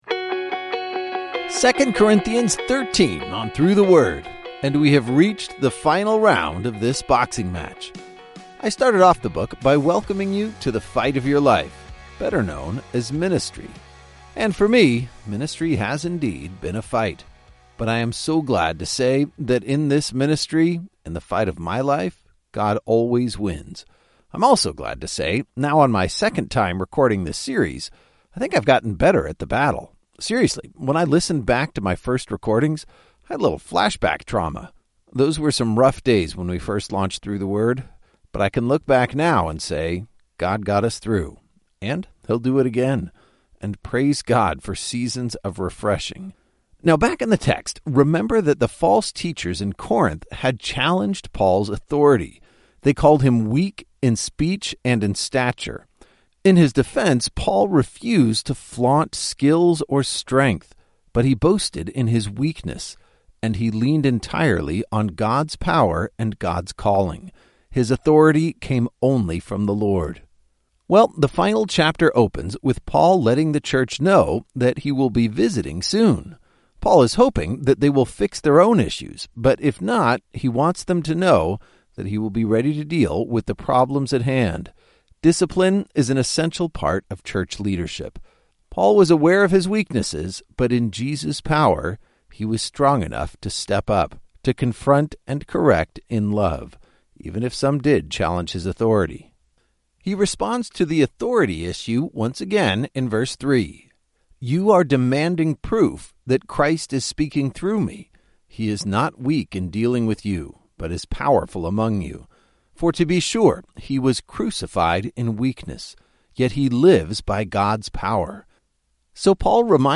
When the Bible is confusing, Through the Word explains it–with clear and concise audio guides for every chapter.